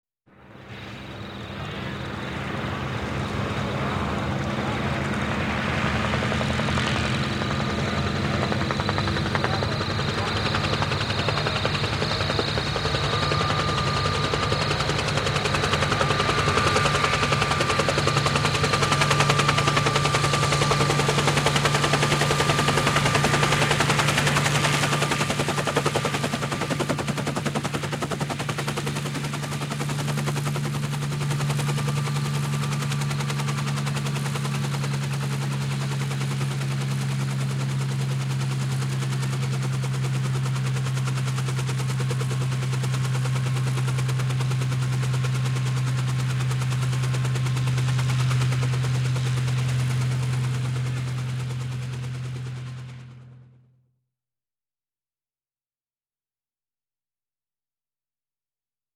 Звуки вертолетов
Шум садящегося вертолета